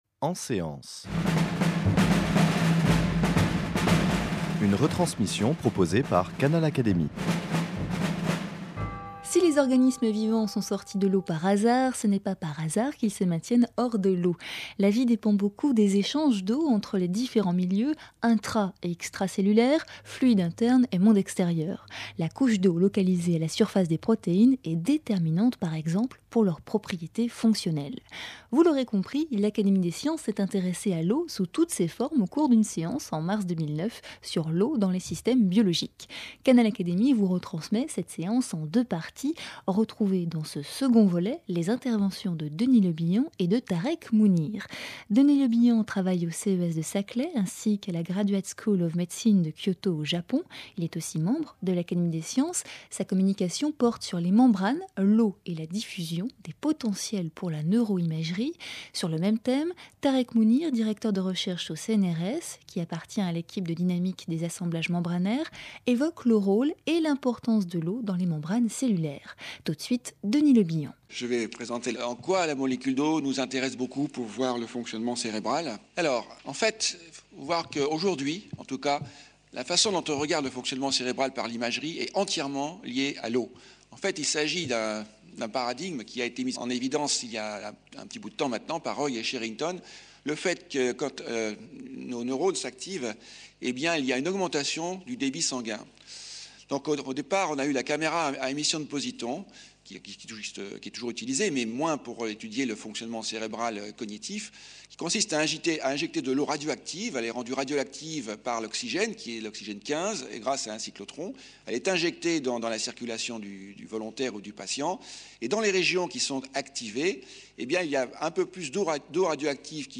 L’Académie des sciences a consacré une séance à l’eau dans les systèmes biologiques en mars 2009. Retransmission vous en est faite en deux parties sur Canal Académie. Dans cette seconde émission, les deux intervenants évoquent tout particulièrement l’eau dans les membranes cellulaires et dans le cerveau.